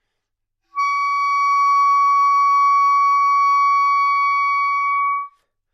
单簧管单音 " 单簧管 Csharp6
Tag: 好声音 单注 单簧管 多重采样 纽曼-U87 Csharp6